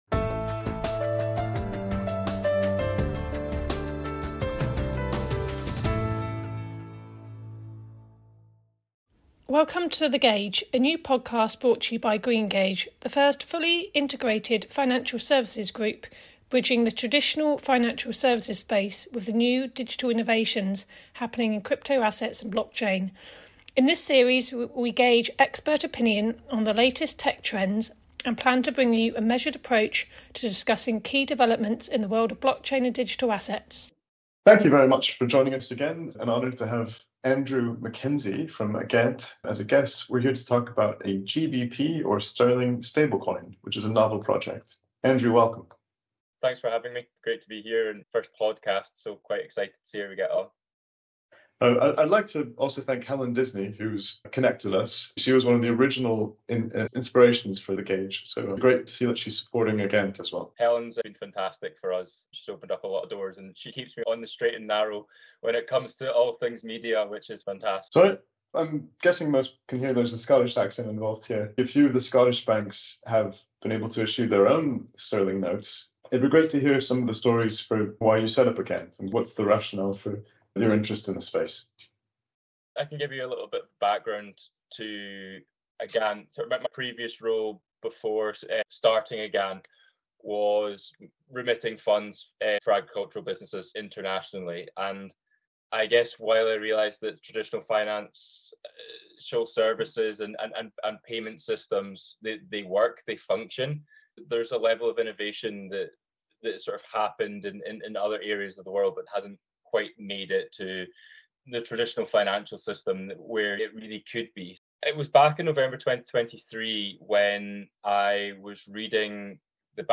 He highlights the need for innovation in traditional finance, the advantages of stablecoins in cross-border transactions, and the importance of regulatory support. The conversation also touches on the future of central bank digital currencies (CBDCs) and the role of public versus private blockchains in the stablecoin market.